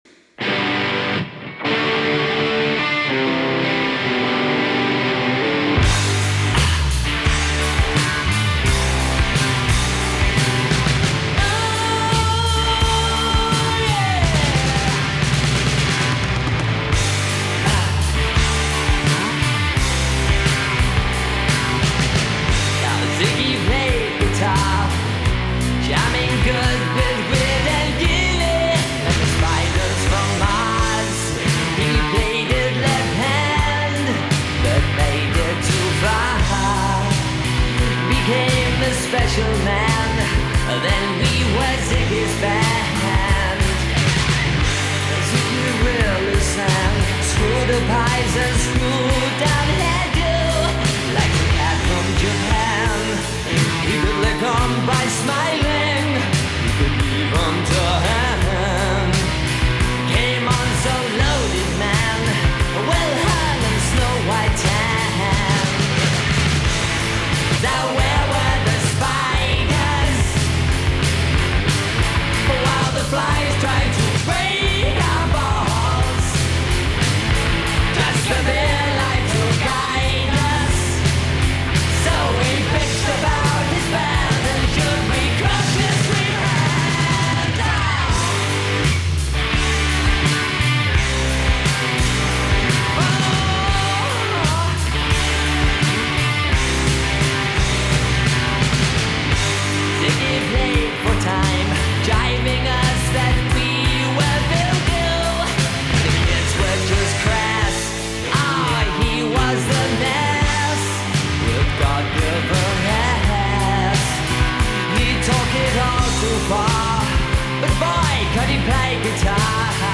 dark, brooding songs
generally considered to be the first known example of Goth.